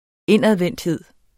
Udtale [ ˈenˀaðvεnˀdˌheðˀ ]